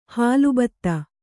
♪ hālu batta